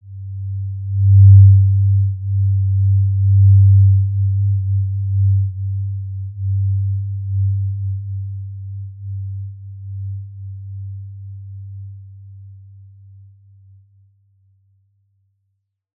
Basic-Tone-G2-mf.wav